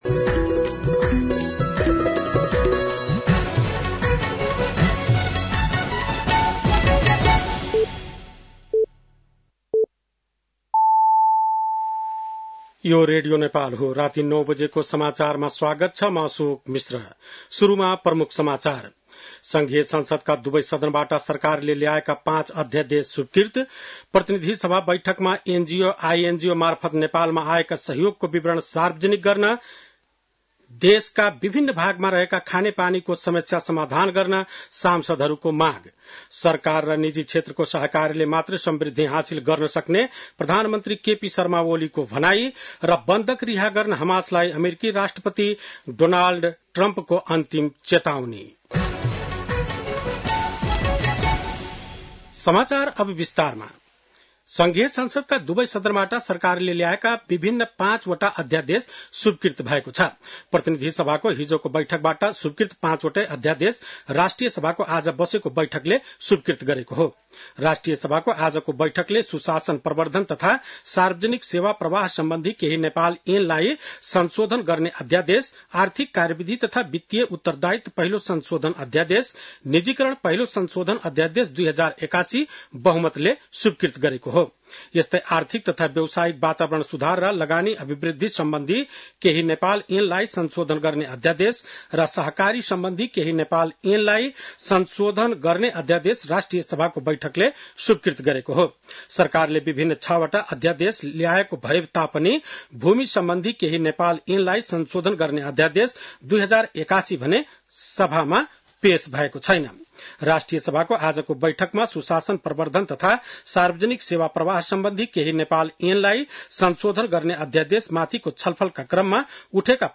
बेलुकी ९ बजेको नेपाली समाचार : २३ फागुन , २०८१